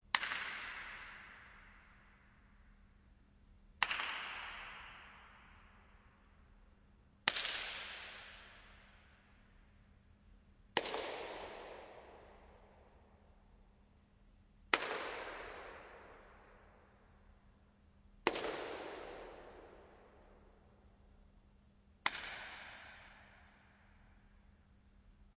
手拍大空间
描述：在一个大的空地上有几个音调不同的手拍。
声道立体声